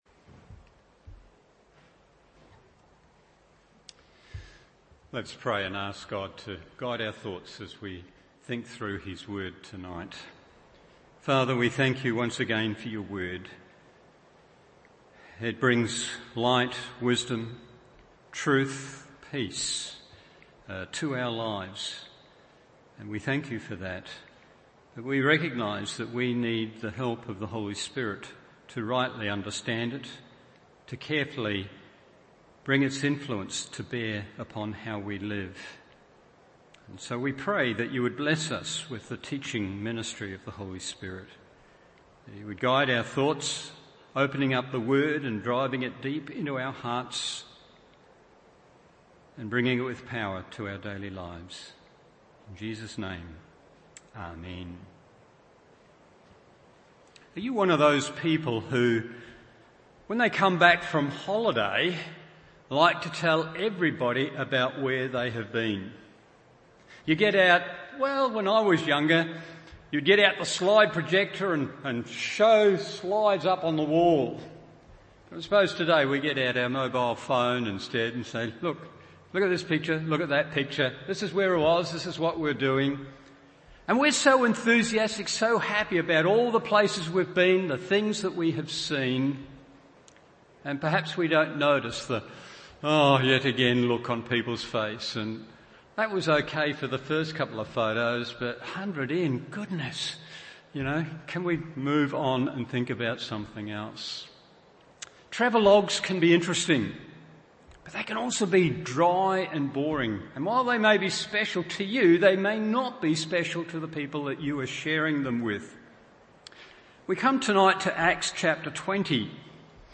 Evening Service Acts 20:1-16 1. Its Posture 2. Its Participation 3.